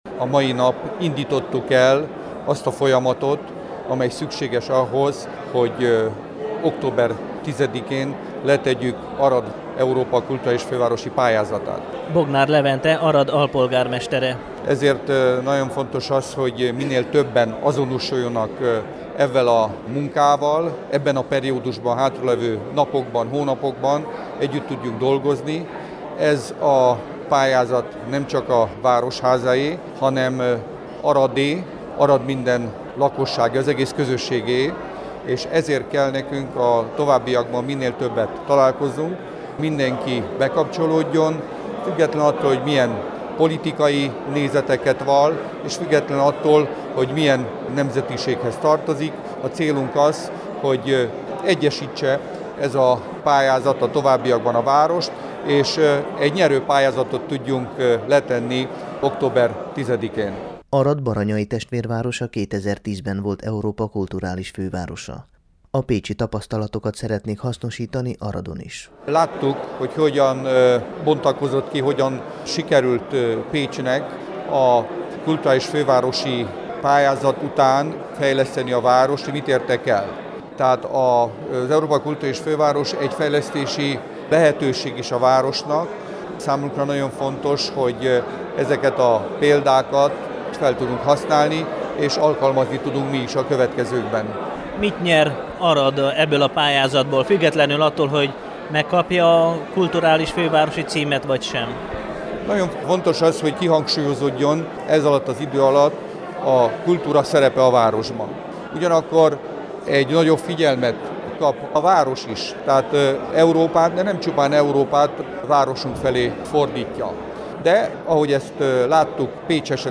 Szerda este jelentették be ünnepélyes keretek között a színházban, hogy Arad megpróbálja elnyerni a 2021-es Európa Kulturális Fővárosa-címet.